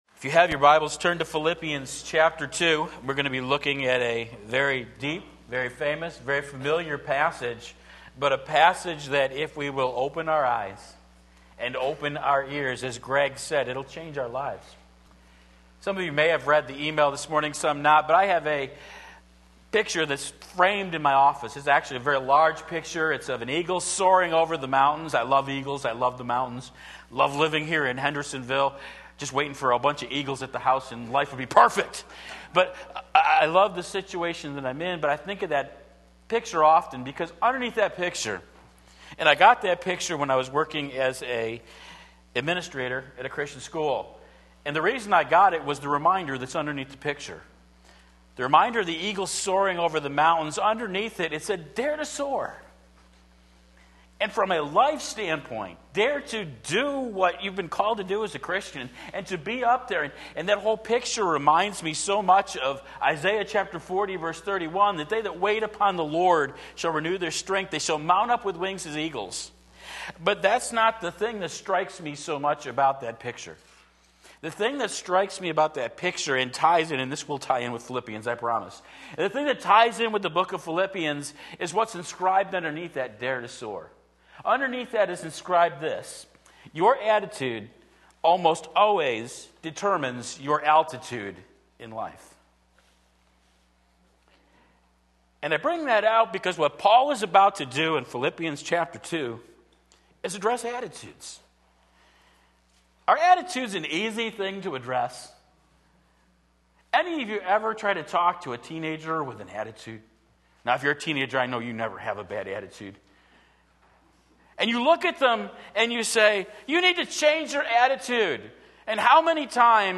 Sermon Link
Sunday Morning Service